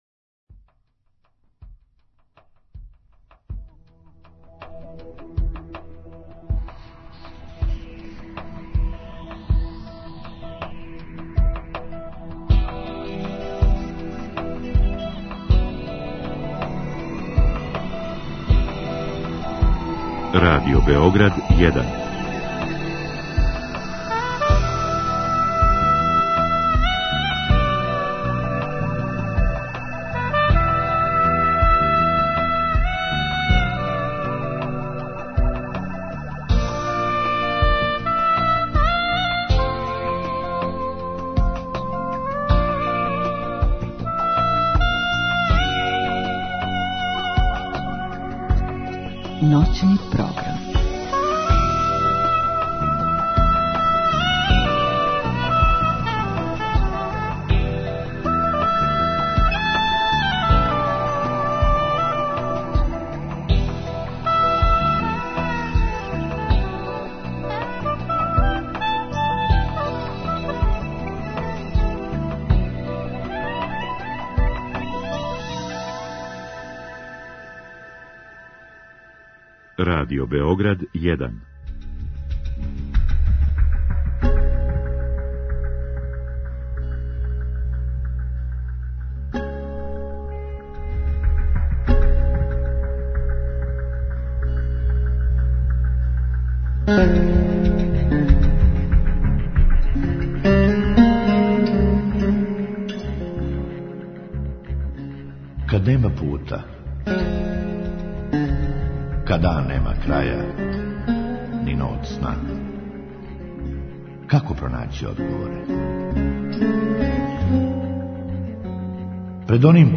Други сат је резервисан за слушаоце који ће у директном програму моћи да поставе питање гошћи.